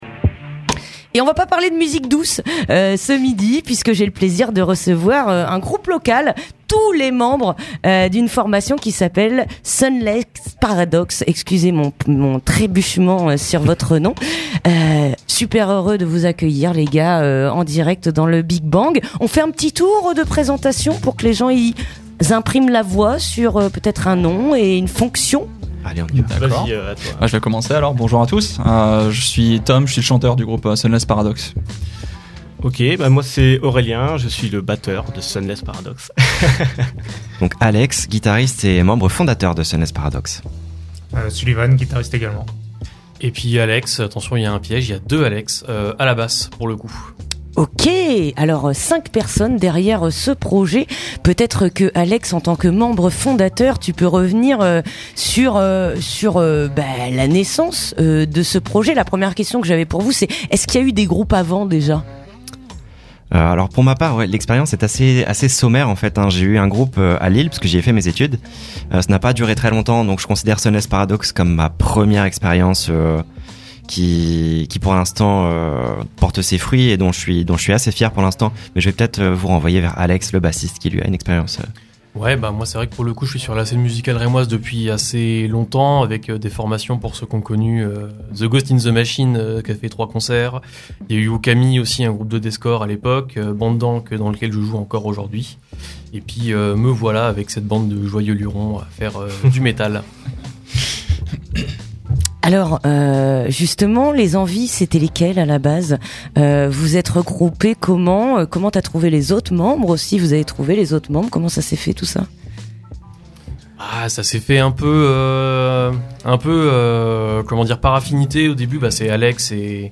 Interview du 12 septembre (13:42)